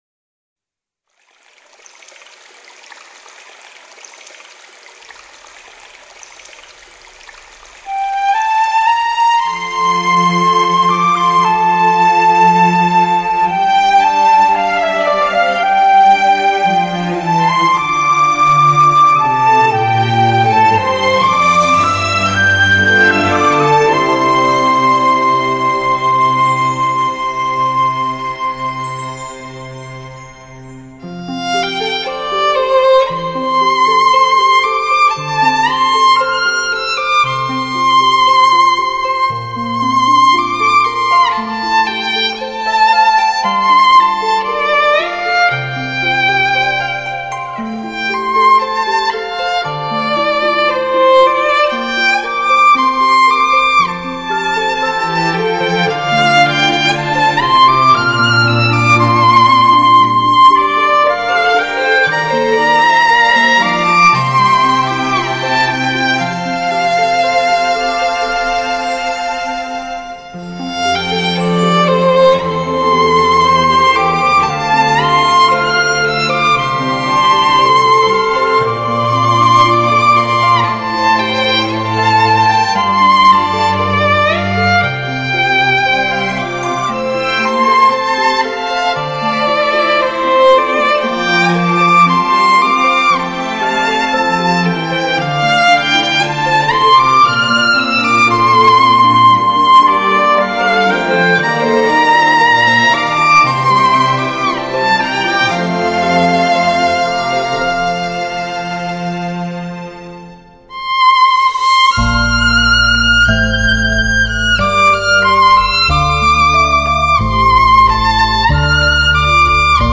专辑格式：DTS-CD-5.1声道
细腻、洞悉内心的演绎，充满柔和亲昵的情感。
兼备华丽、浪漫、缠绵的动人元素。
吉他、弦乐组、长笛、
古筝等乐器演奏的纯音乐专辑。
音乐曲目选择了不少经典的流行歌曲，DSD录音。